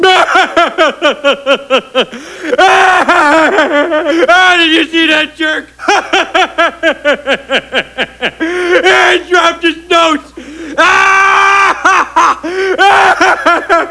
Homer Laughs - Matter Transporter -
laugh.wav